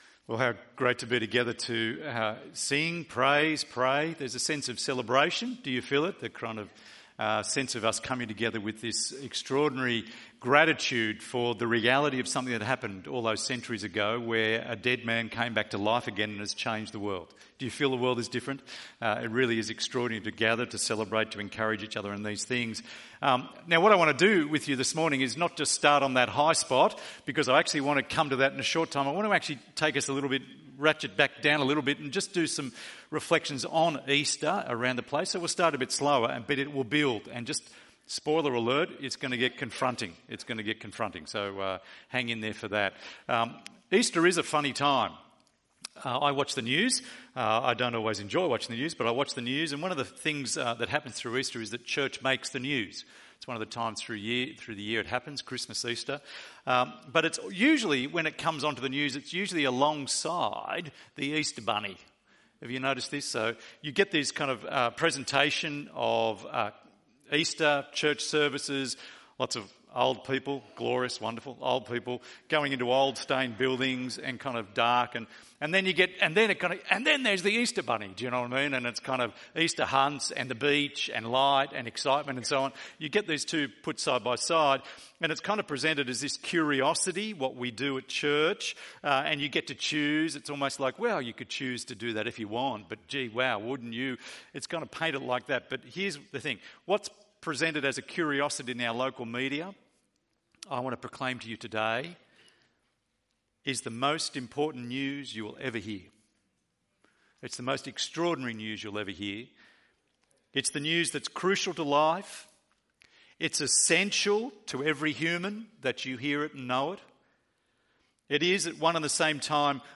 Celebrate Easter - Easter Sunday ~ EV Church Sermons Podcast